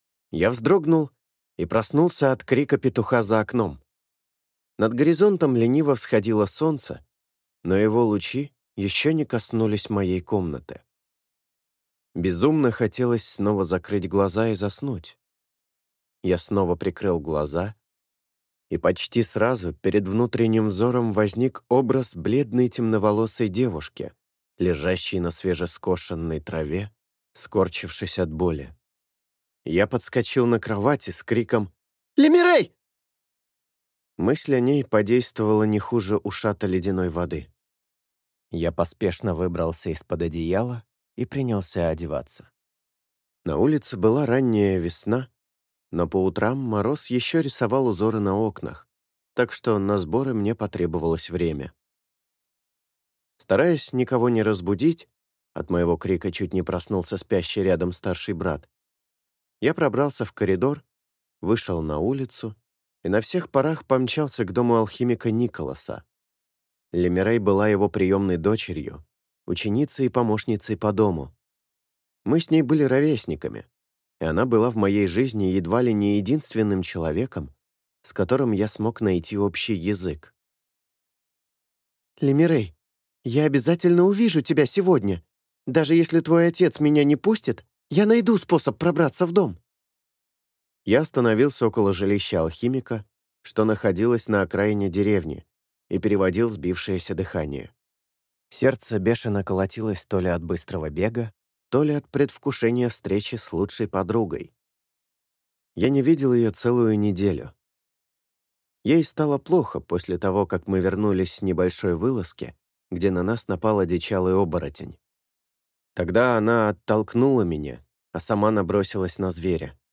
Аудиокнига Ночь пламени | Библиотека аудиокниг